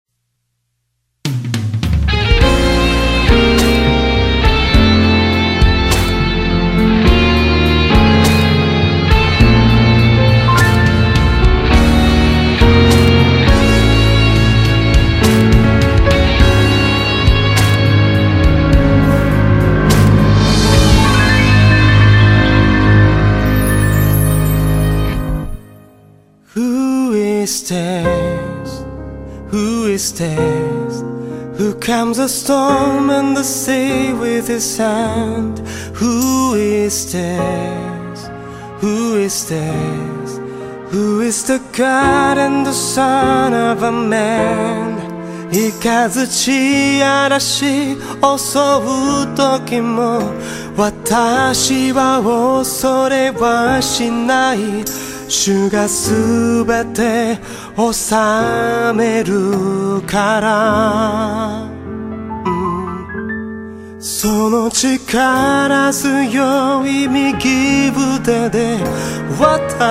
R&Bからジャズまで幅広い音楽的要素を持ち